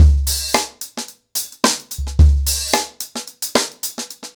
HarlemBrother-110BPM.21.wav